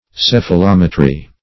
\Ceph`a*lom"e*try\